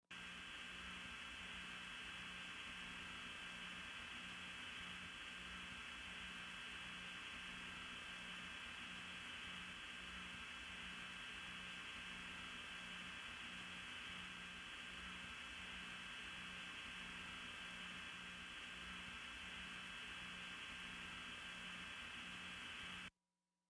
Telephone line noise, old rural type